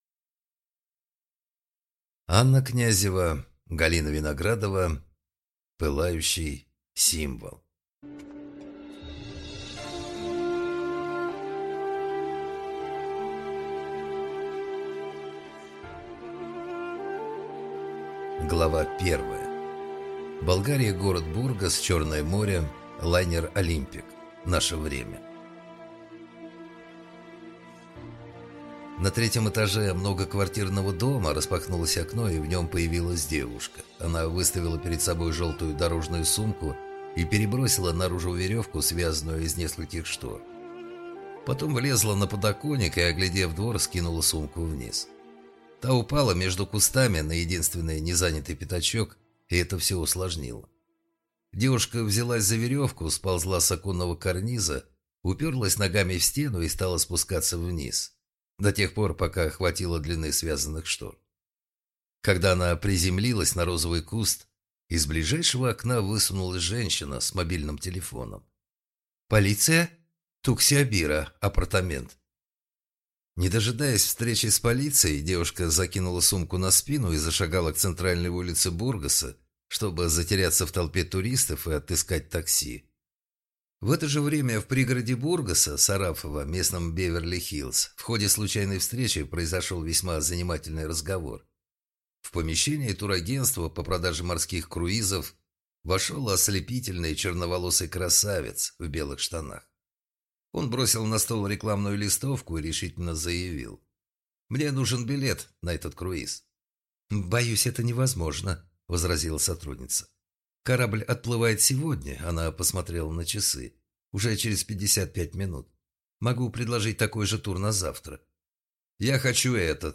Аудиокнига Пылающий символ. Том 1 | Библиотека аудиокниг